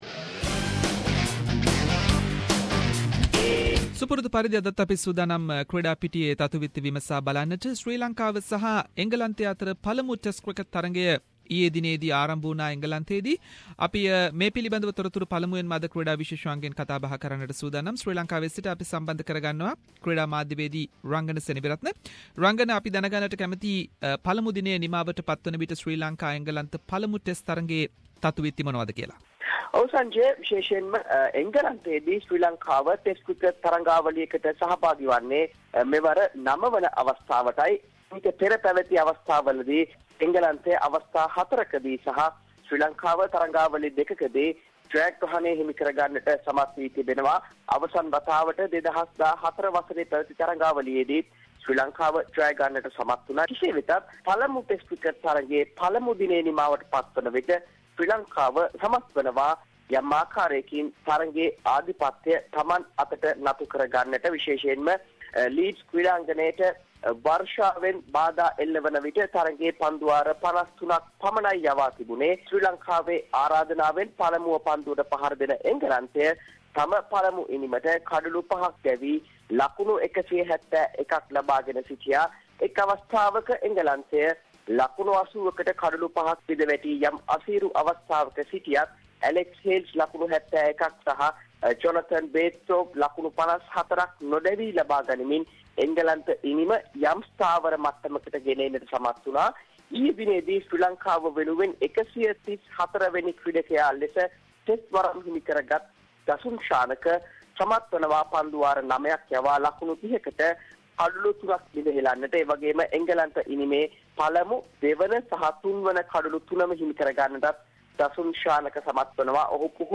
In this weeks SBS Sinhalese sports wrap…. Latest from Sri Lanka cricket tour to England, ICC denies SLC asked for reimbursement for Kusal Janith Perera and many more sports news.